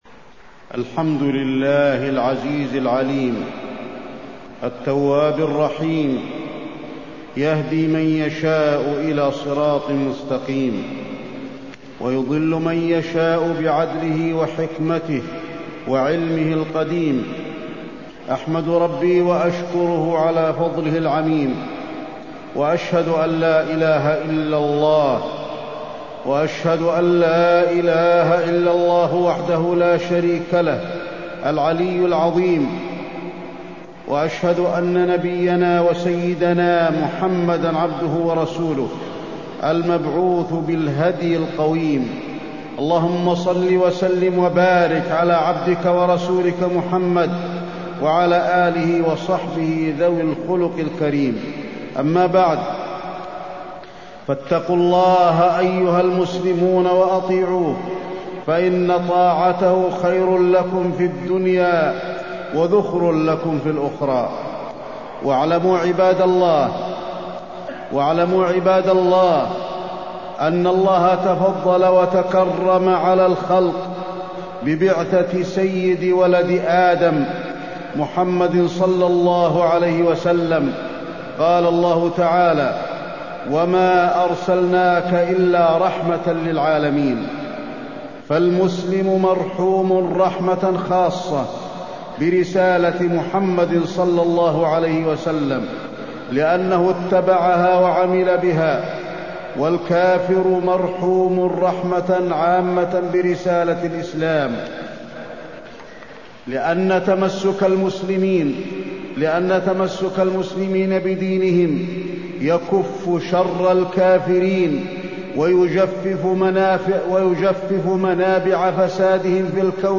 تاريخ النشر ٩ ربيع الثاني ١٤٢٥ هـ المكان: المسجد النبوي الشيخ: فضيلة الشيخ د. علي بن عبدالرحمن الحذيفي فضيلة الشيخ د. علي بن عبدالرحمن الحذيفي التمسك بالسنة